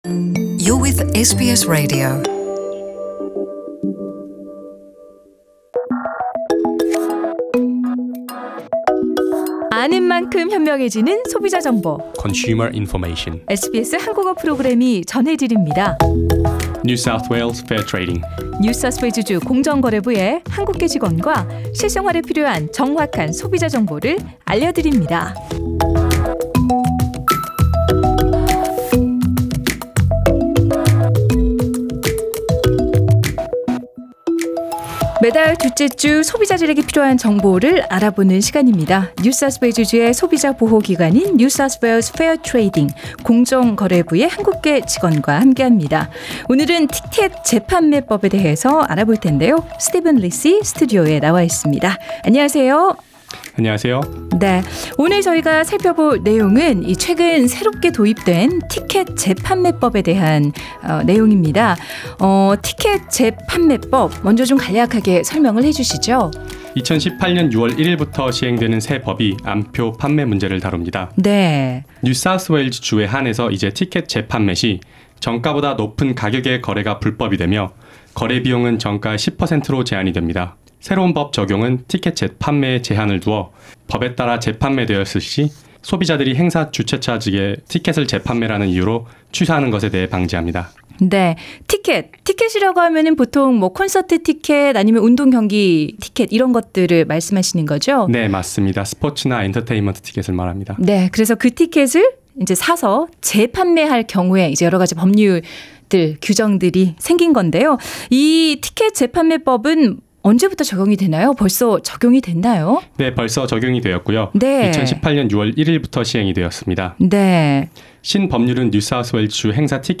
Consumer Information is a radio segment brought by NSW Fair Trading, the consumer protection agency.